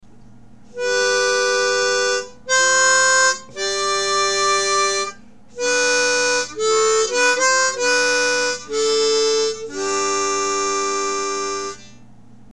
We’re using a G major diatonic and playing in 1st position.